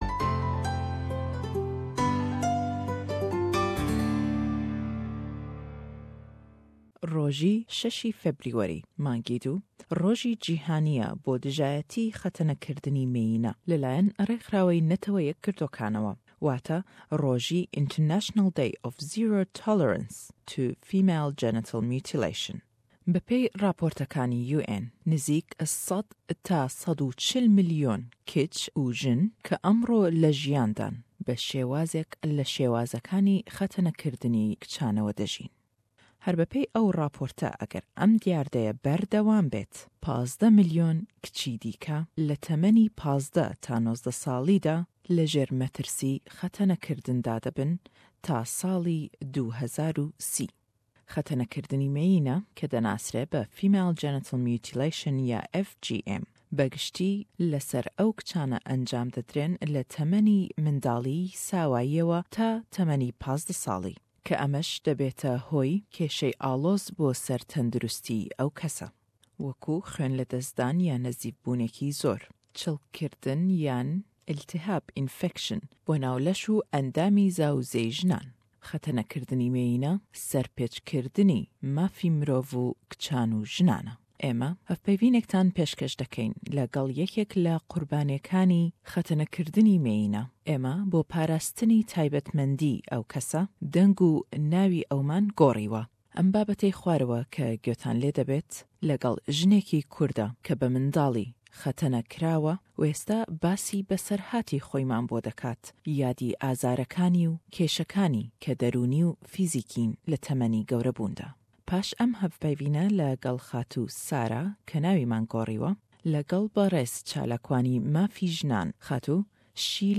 Em hevpeyvîn sallî 2013 tomar kirabû û be boney rojî cîhanî bo dijayetî xetene-kirdinî mêyine (6/2) dûbare billawî dekeynewe.